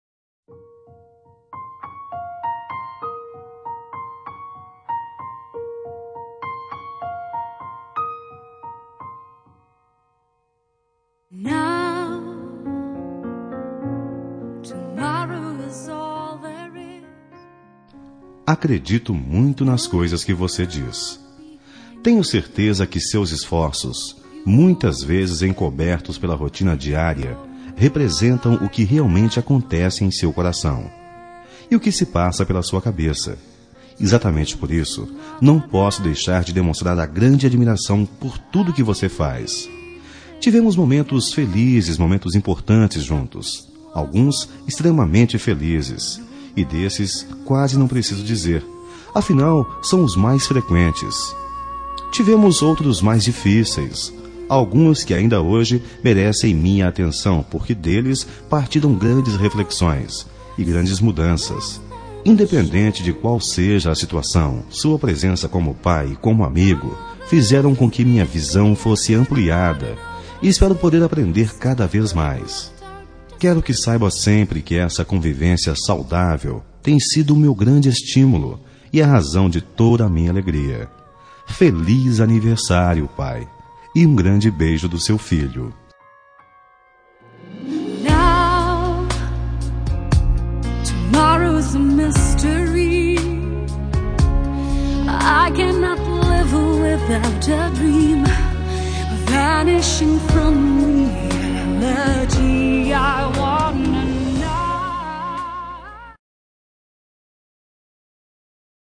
Telemensagem de Aniversário de Pai – Voz Masculina – Cód: 1496 Linda